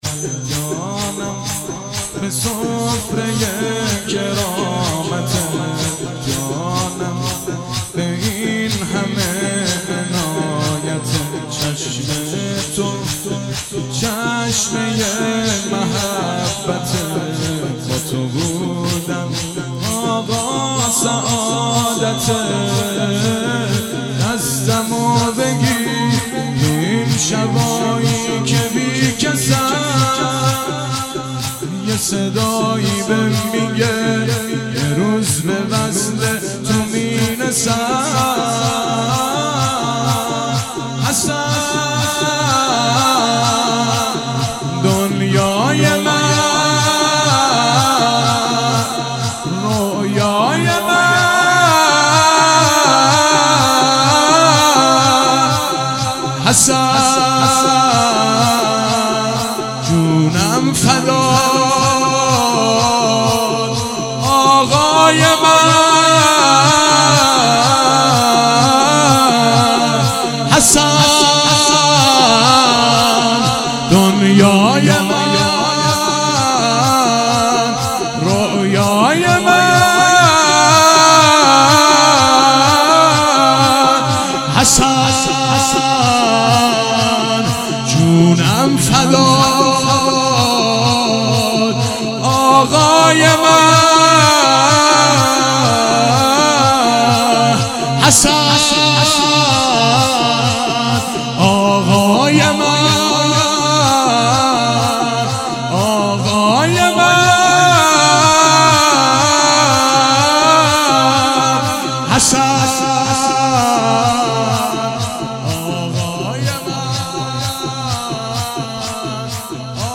شهادت امام حسن (ع)- شور - ای جانم به سفره کرامتت